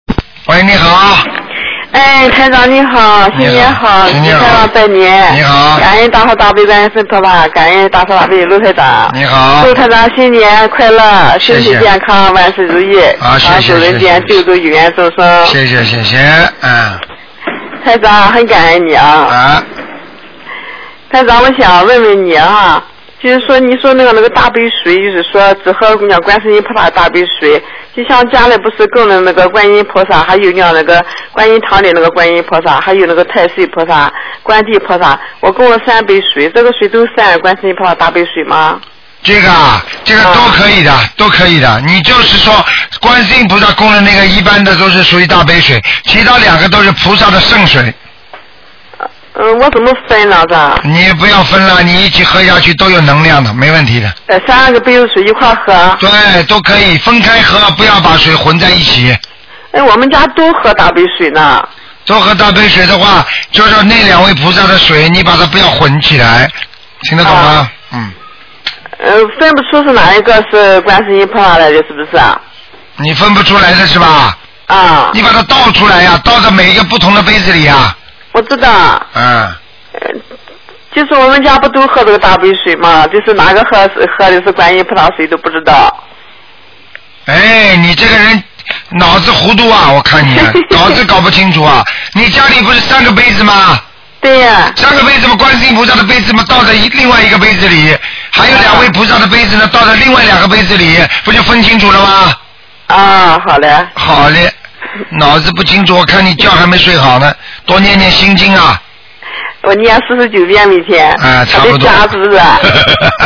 Tanya Jawab
Pendengar wanita: Master,  di altar rumah saya, kami menyembahyangi Guan Shi Yin Pu Sa dari Guan Yin Tang, Tai Sui Pu Sa, Guan Di Pu Sa, lalu saya mempersembahkan 3 gelas air minum, apakah ketiganya bisa dikatakan sebagai air Da Bei Shui dari Guan Shi Yin Pu Sa?